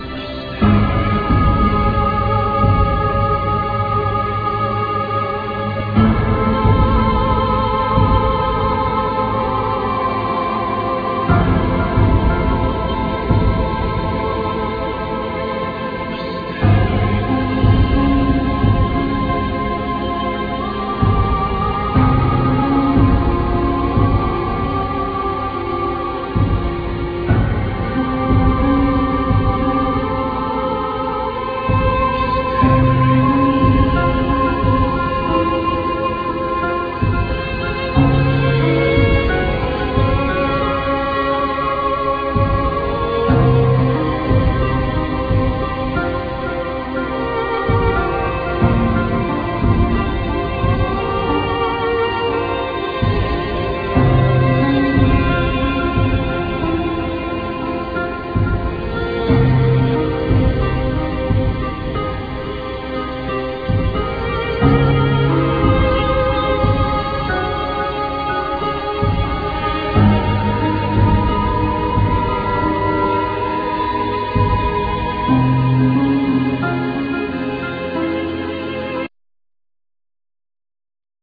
All insturuments, vocals, sound-design, programming
Trumpet, Horn, Trombone
Soprano
Violin, Viola
Violin, Solo violin